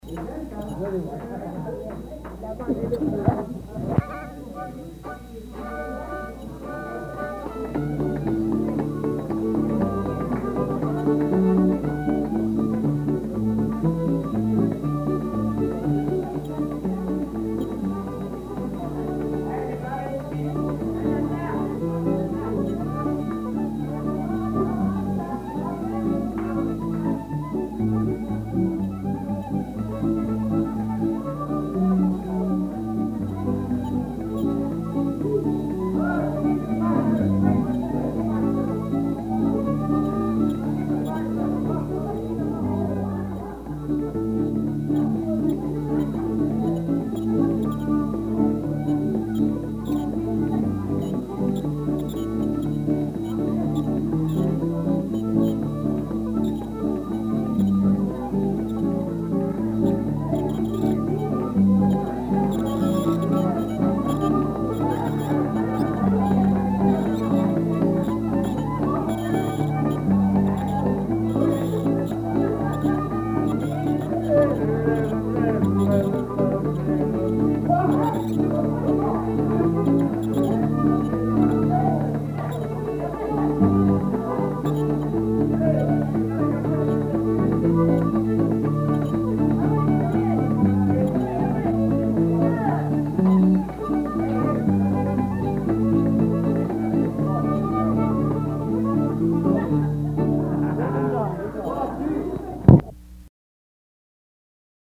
Folk Songs, French--New England
Franco-Americans--Music